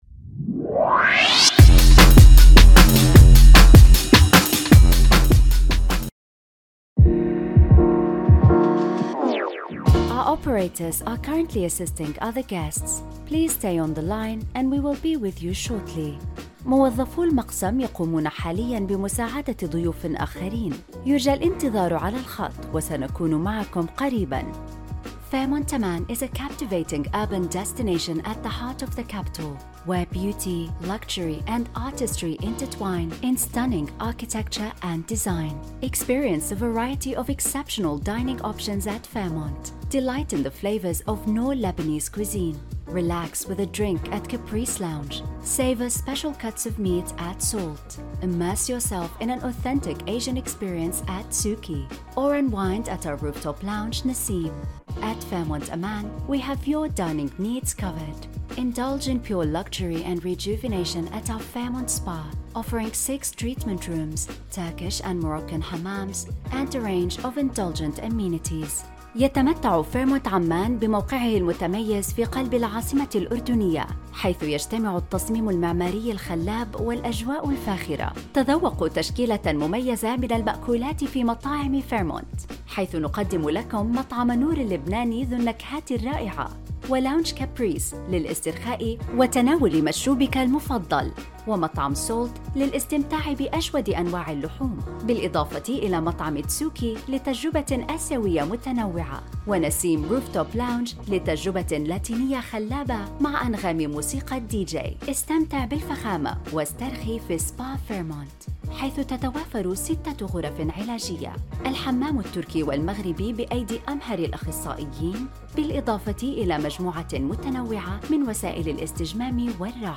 Fairmont IVR.
Voice Over - IVR